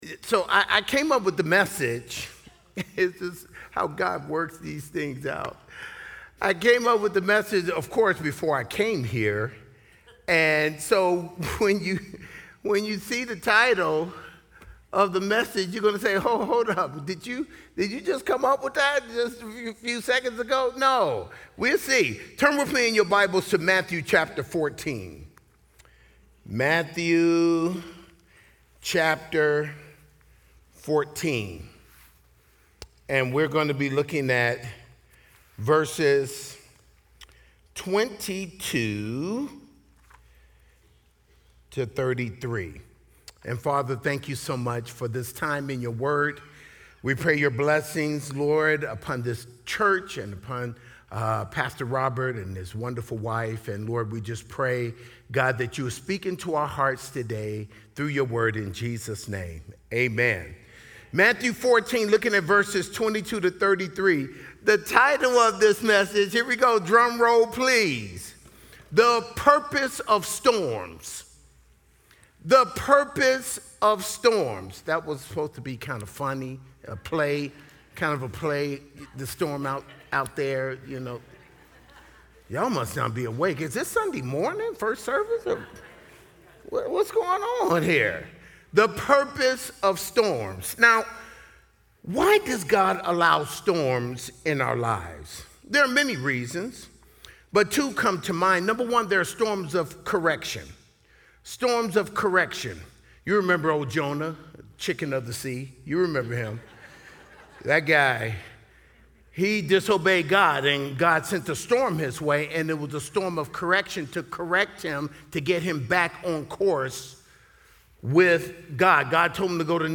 Special Guest Speaker